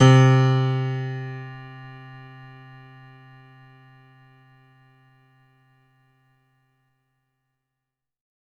55ay-pno07-c2.wav